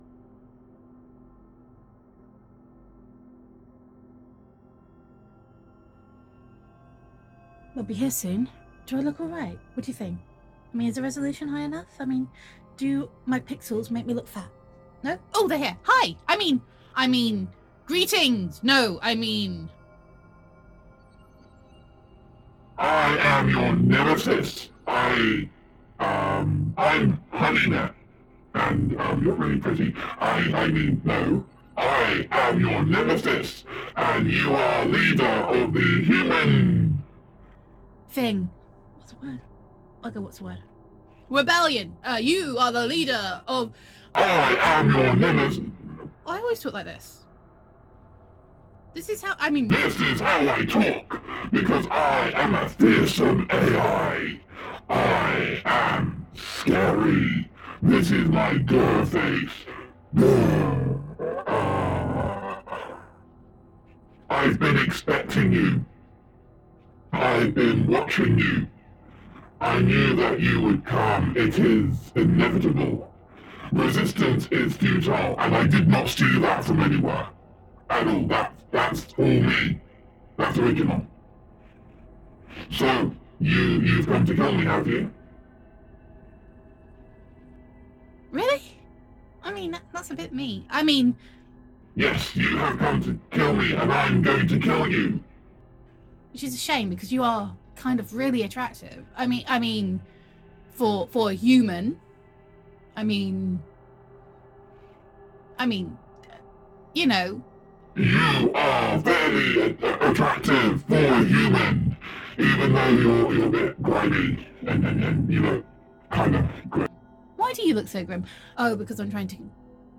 [F4A] HoneyNet
[AI Roleplay]